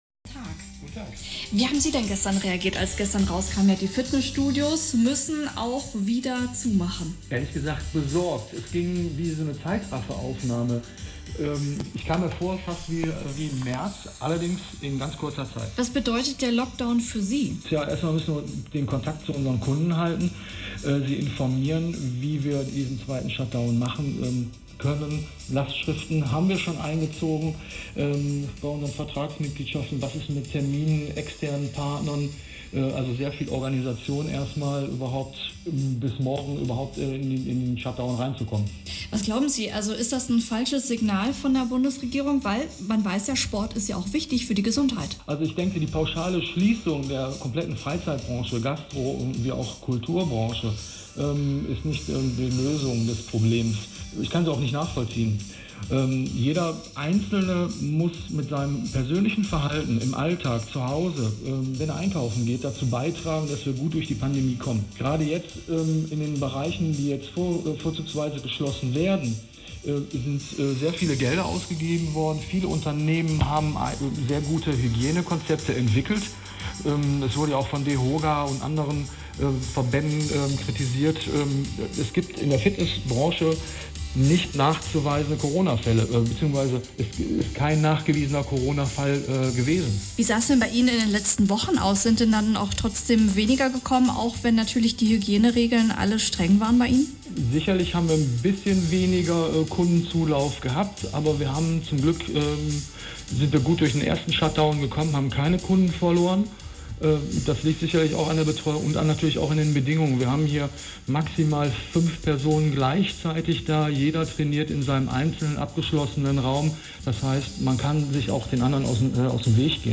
Hier findet Ihr das geführte Interview mit Radio Essen vom 29.10.2020